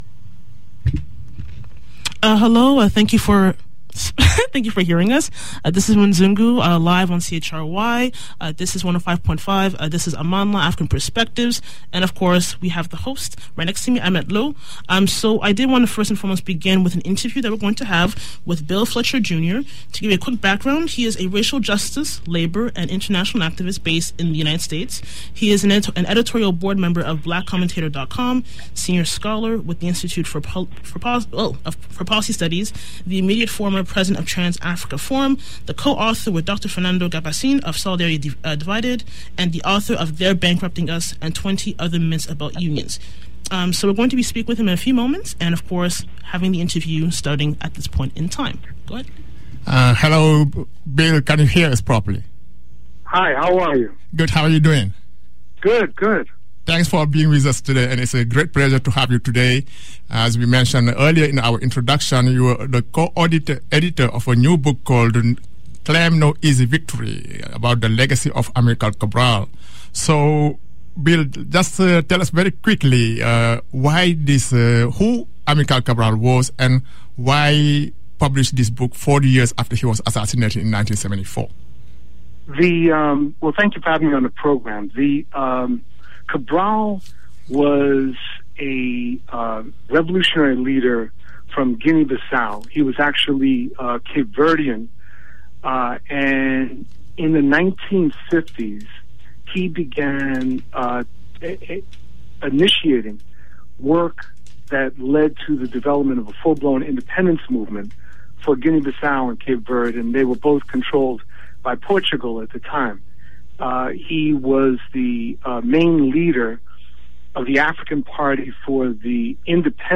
CHRY_LOG_-_Sunday_February_9_-_interview_-_1200.mp3